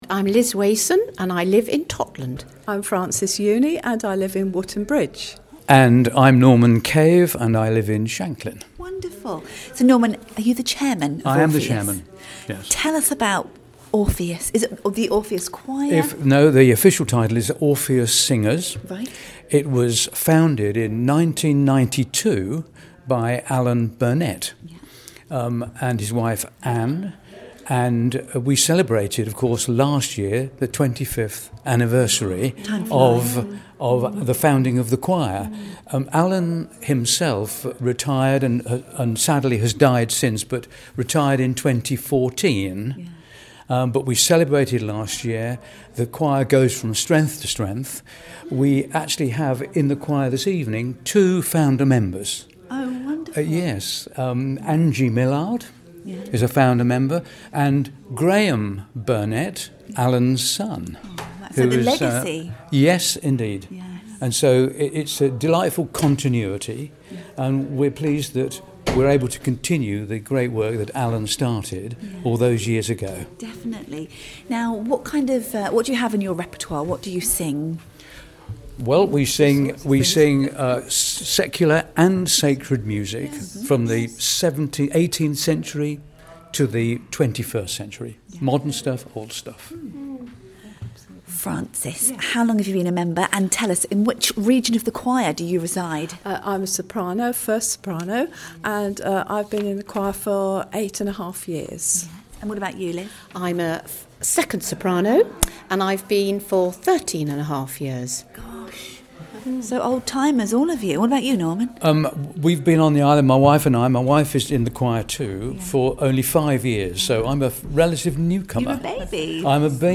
Wonderful choral music….